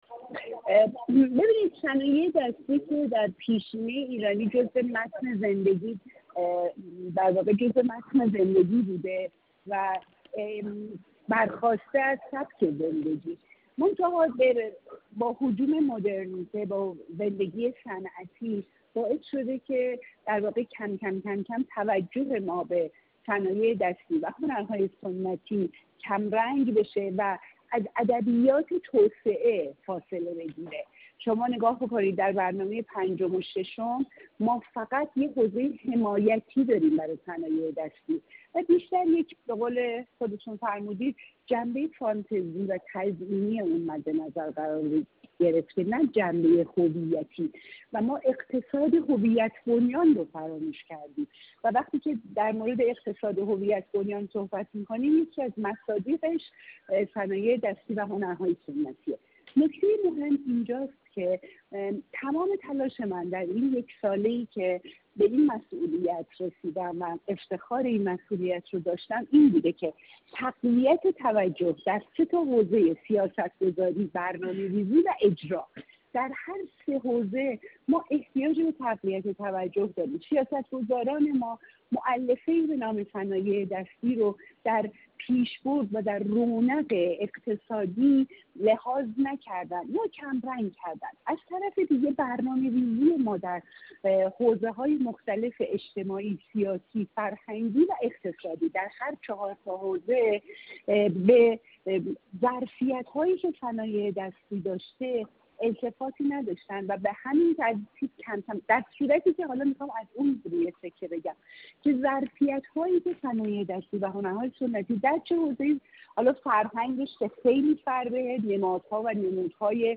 جلالی در گفت‌وگو با ایکنا: